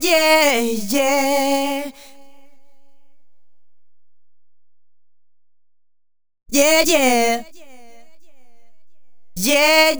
Night Rider - Yeah Yeah Vox.wav